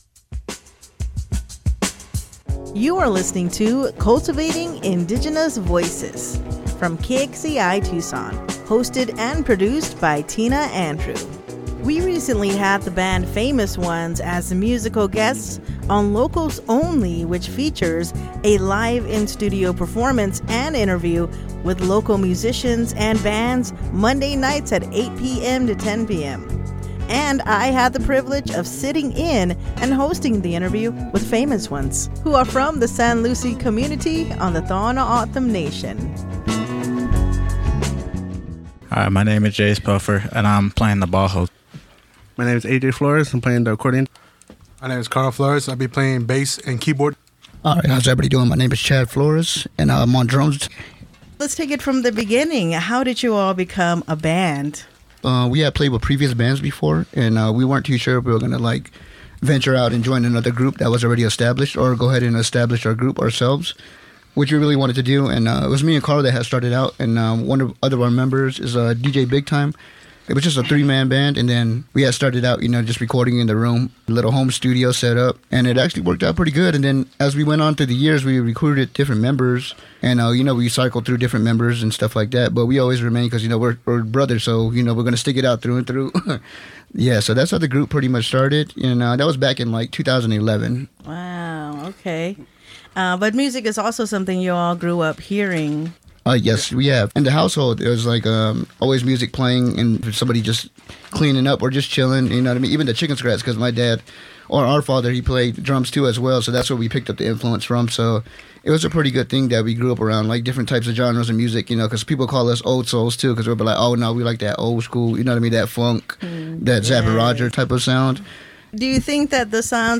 Here is a brief version of the interview that offers key highlights and insights from the full conversation.